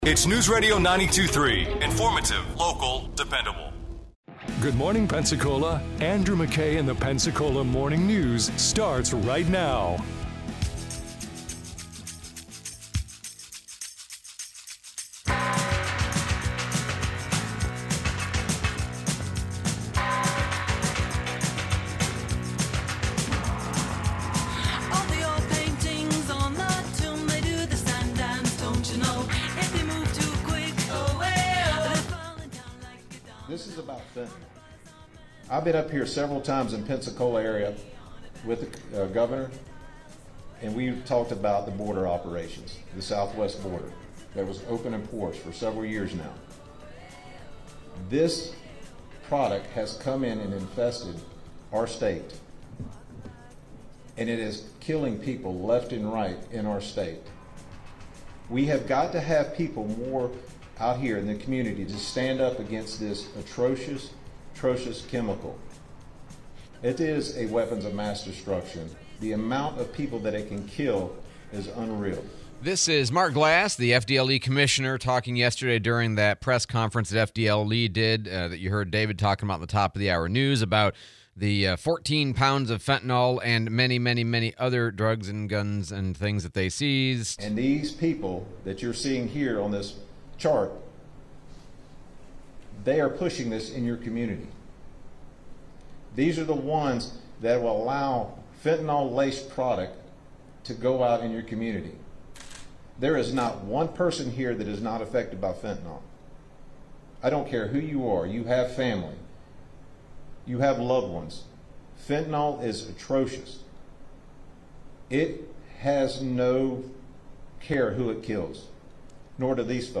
Fentanyl bust, Congressman Patronis interview